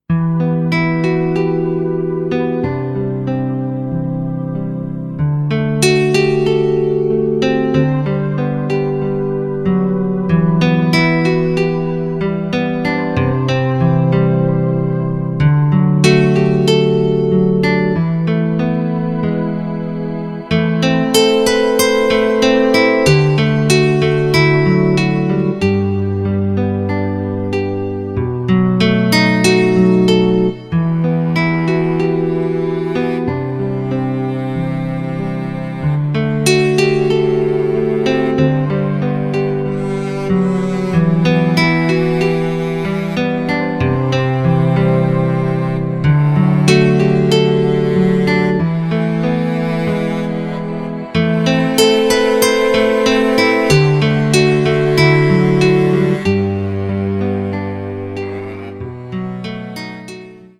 Категория : Мелодии и Звонки на Будильник